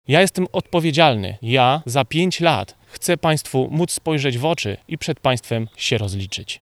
Podczas konferencji zorganizowanej w Parku Dzikowskim